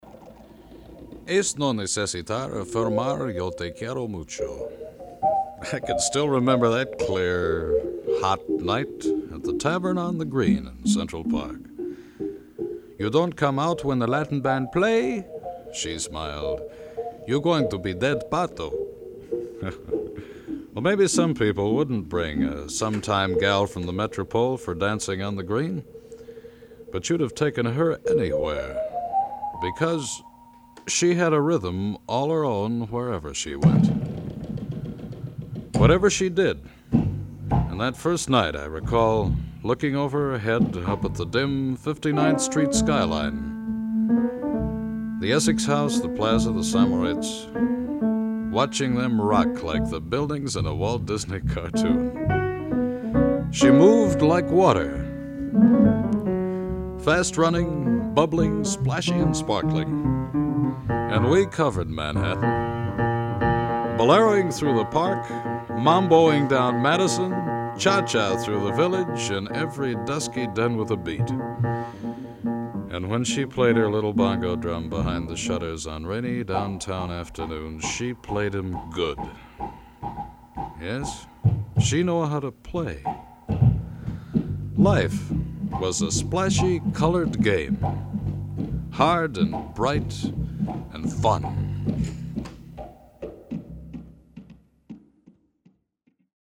evocative writing style and commanding vocal gift